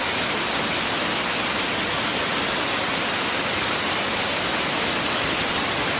Free SFX sound effect: Rock Slide.
Rock Slide
# rock # slide # rumble About this sound Rock Slide is a free sfx sound effect available for download in MP3 format.
349_rock_slide.mp3